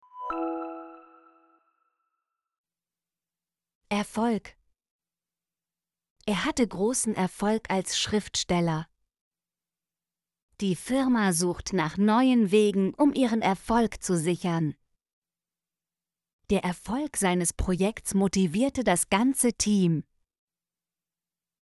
erfolg - Example Sentences & Pronunciation, German Frequency List